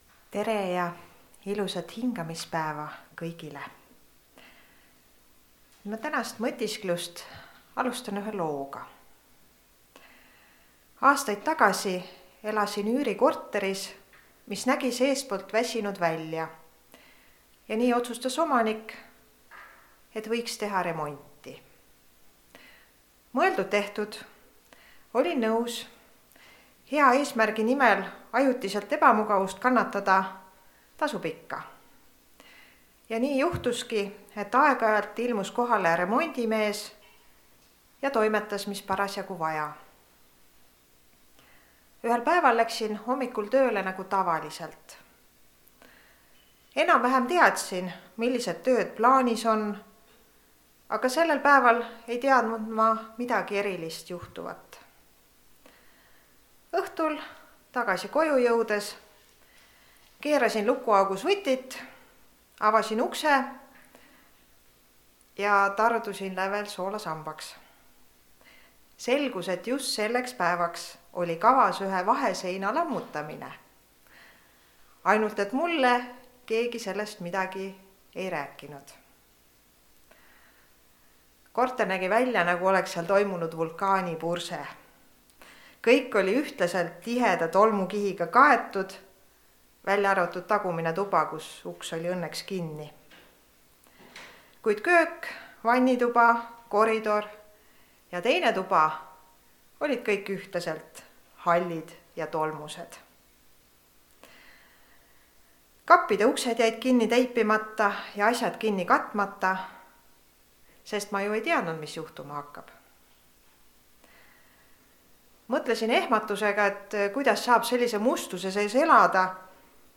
Elevanti süüakse lusikatäite kaupa (Rakveres)
Jutlused